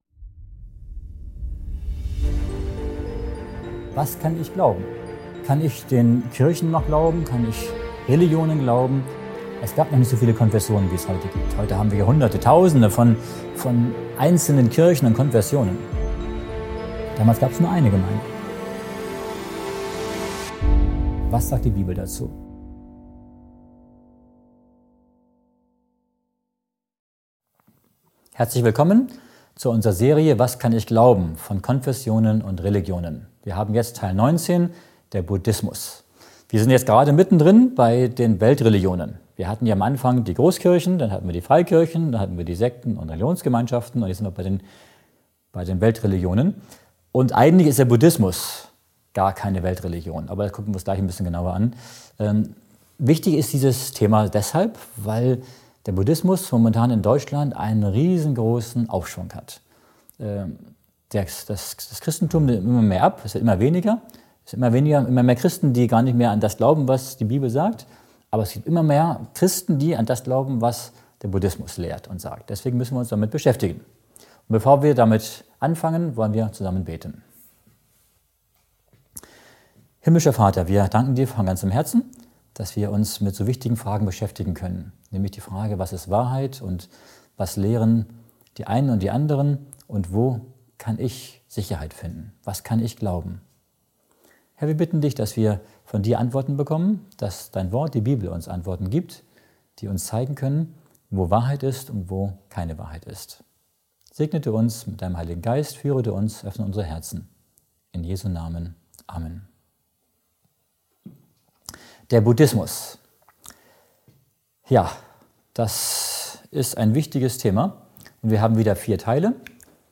Teil 19 der Serie „Was kann ich glauben?“ widmet sich dem Buddhismus als aufstrebender „Religion“ in Deutschland. Der Vortrag beleuchtet Geschichte, Lehren und den Vergleich mit der Bibel sowie die Unterschiede zum Christentum.